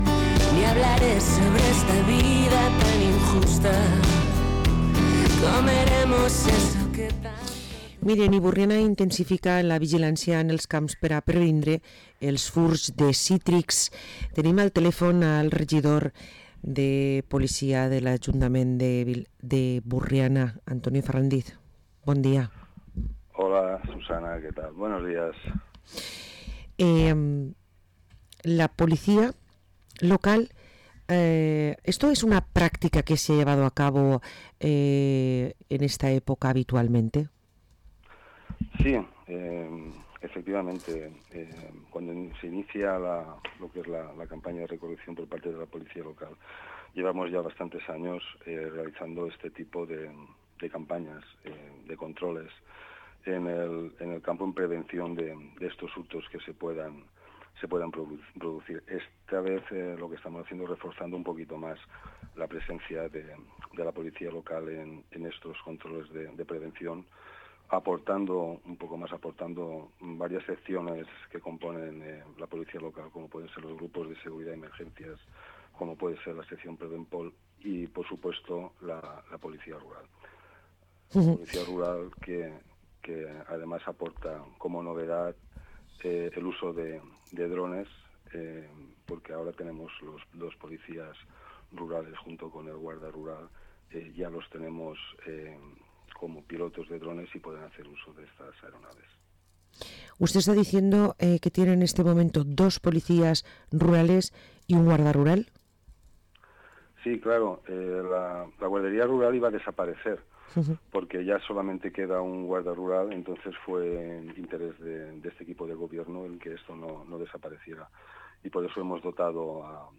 Parlem amb Antoni Ferrándiz, regidor de Seguretat Ciutadana de l’Ajuntament de Burriana